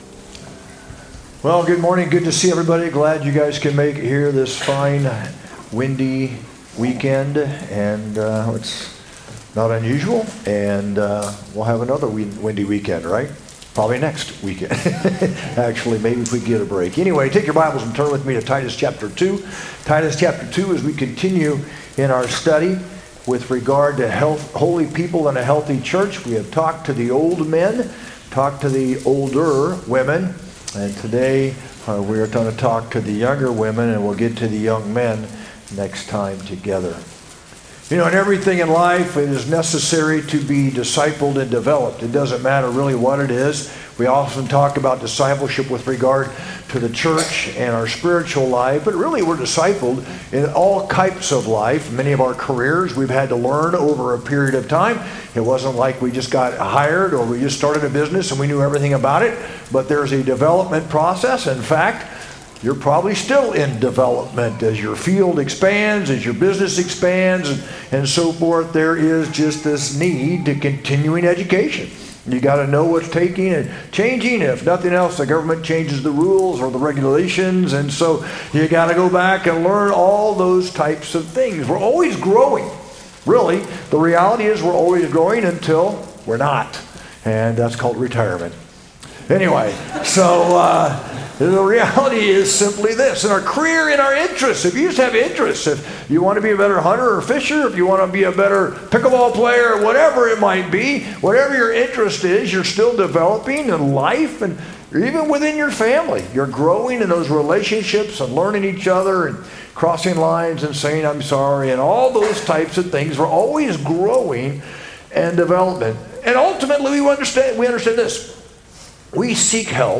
sermon-6-22-25.mp3